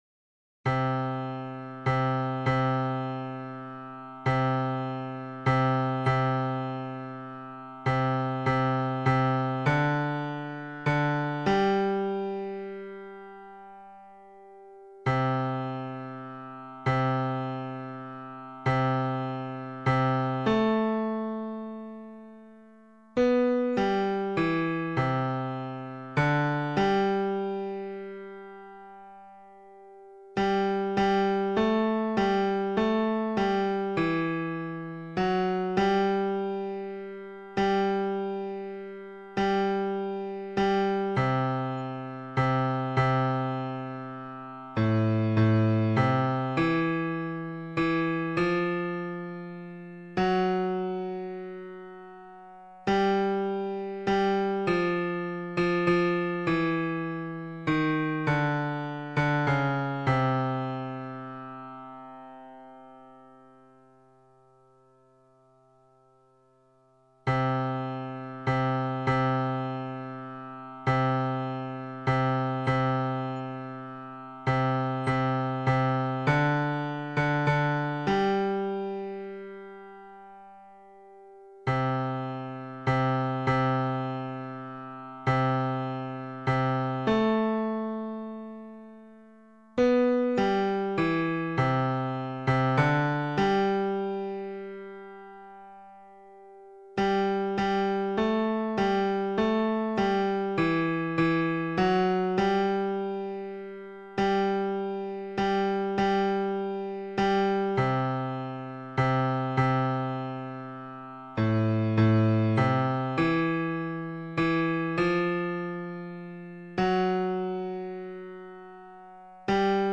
Information and practice vocal scores & mp3's for the